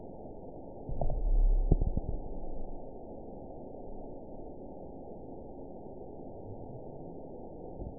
event 916964 date 02/22/23 time 05:02:38 GMT (2 years, 2 months ago) score 9.16 location TSS-AB04 detected by nrw target species NRW annotations +NRW Spectrogram: Frequency (kHz) vs. Time (s) audio not available .wav